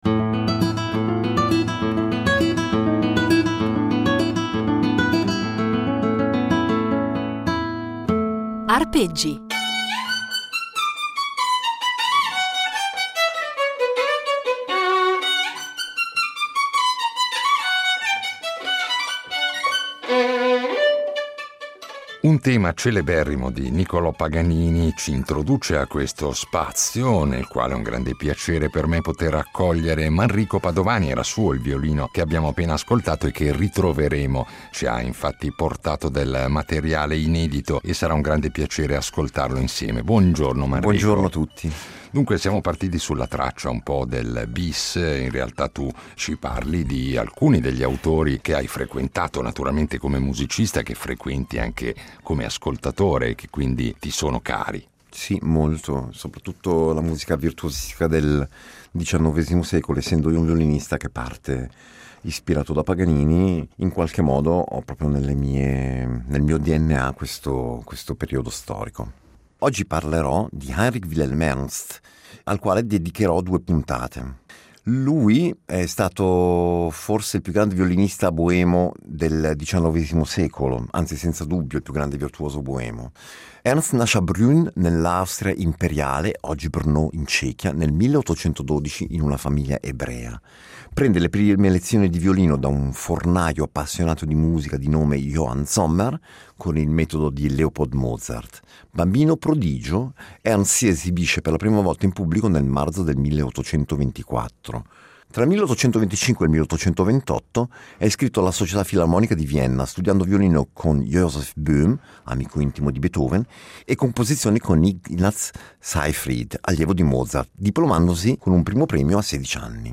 violinista svizzero